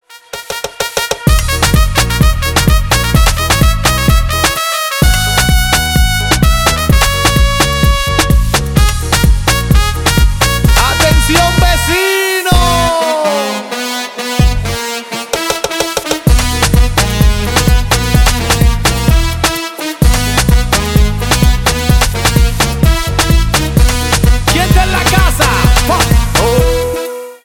Поп Музыка
латинские
клубные